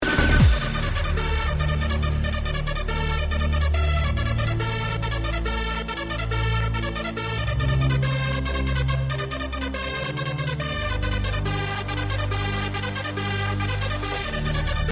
Please Help Identify Unkown trance track